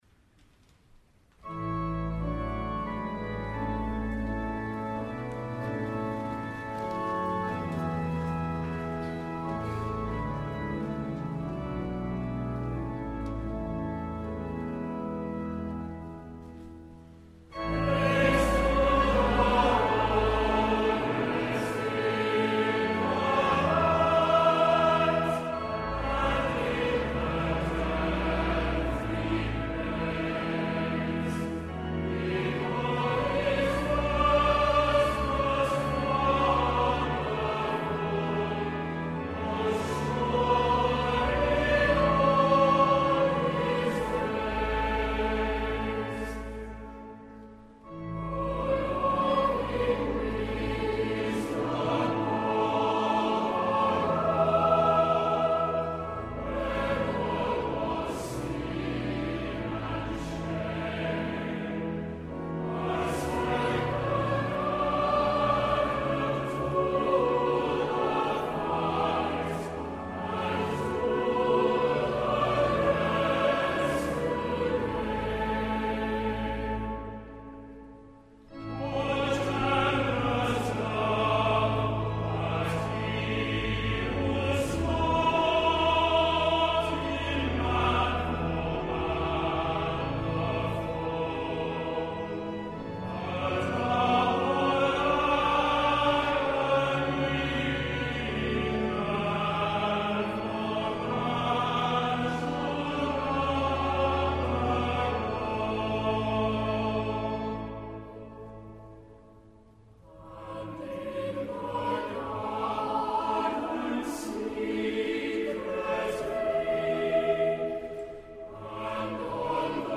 Easter from King’s College, Cambridge
9          Hymn: